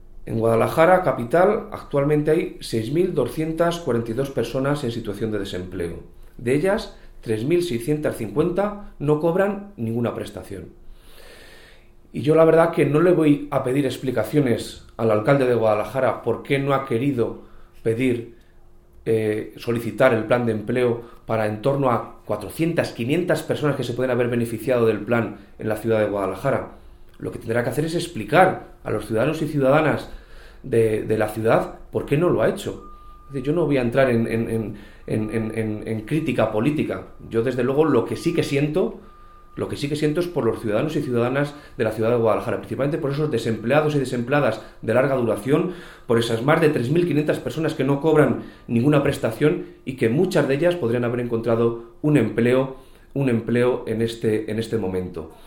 El delegado de la Junta en Guadalajara habla de la decisión del Ayuntamiento de Guadalajara de no acogerse al Plan de Empleo.